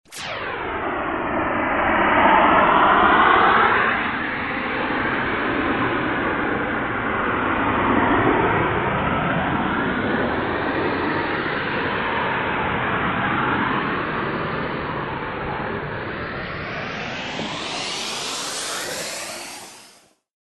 Шум летящей ракеты